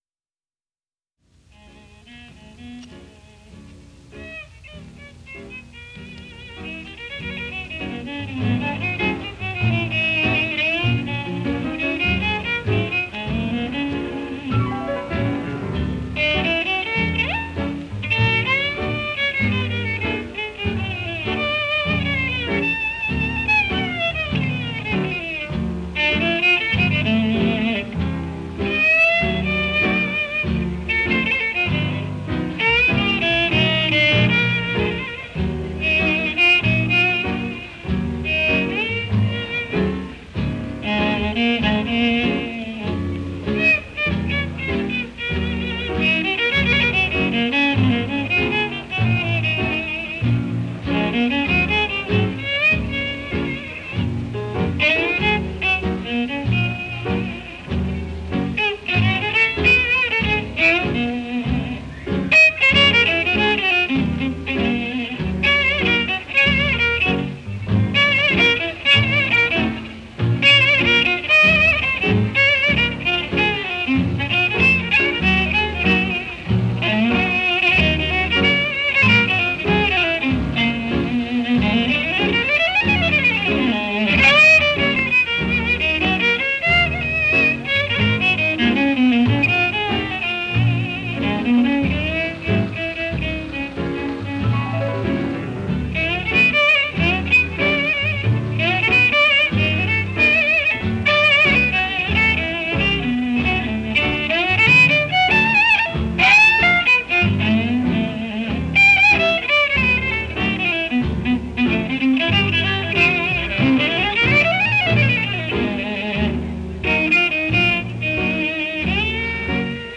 a 1940 broadcast